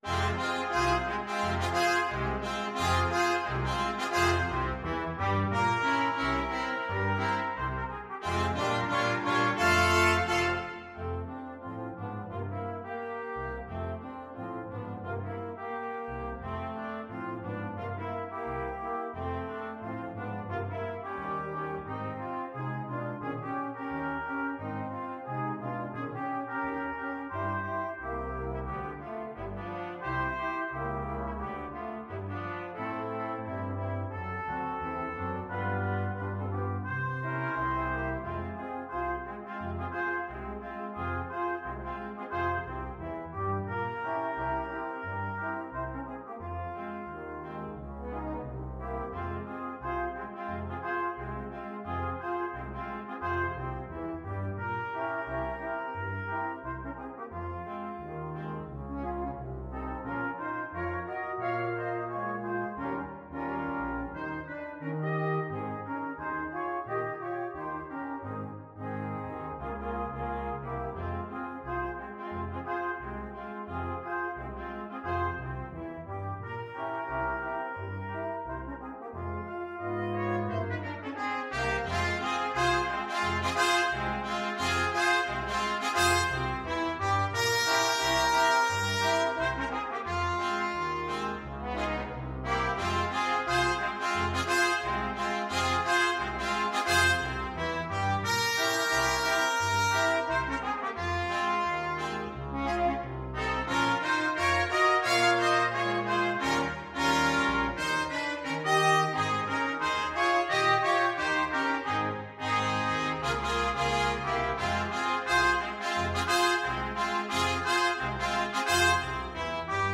Trumpet 1Trumpet 2French HornTromboneTuba
2/2 (View more 2/2 Music)
~ = 176 Moderato
Jazz (View more Jazz Brass Quintet Music)
Rock and pop (View more Rock and pop Brass Quintet Music)